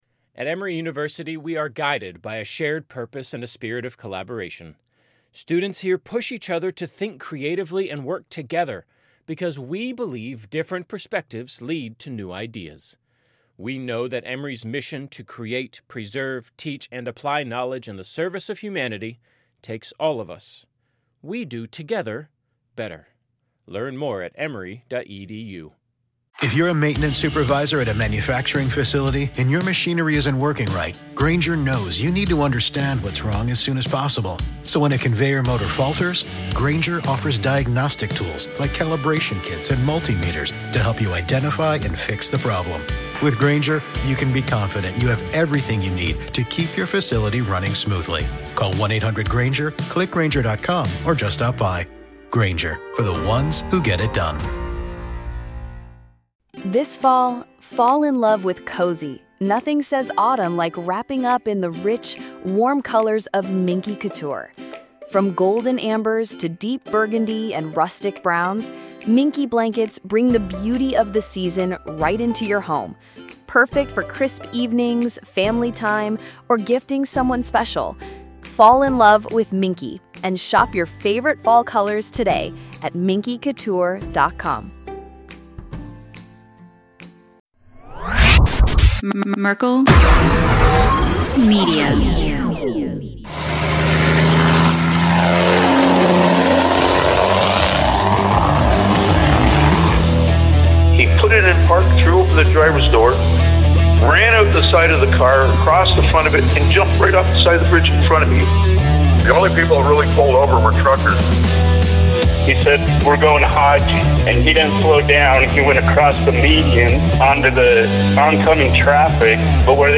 This episode was chalk full of wonderful and hilarious banter. It was like talking with some old friends.